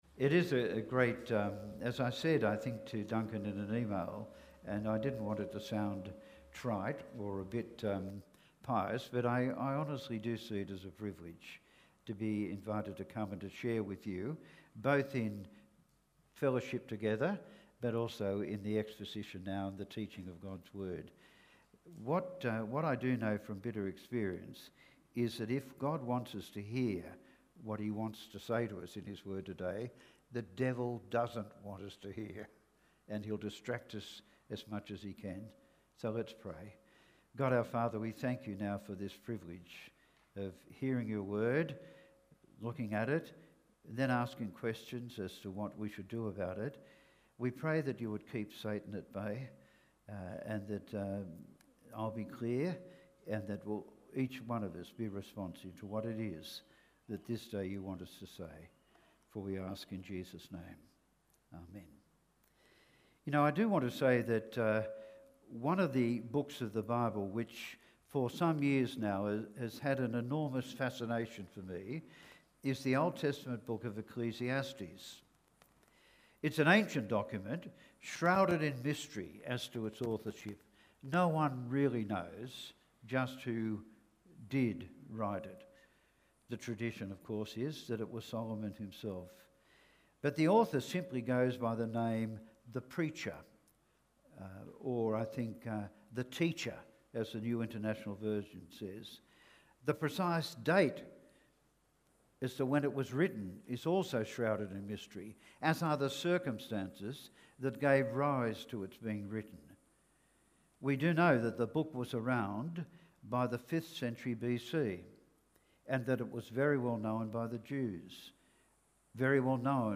A Single Sermon Passage: John 3:16-21, Ecclesiastes 3:1-15 Service Type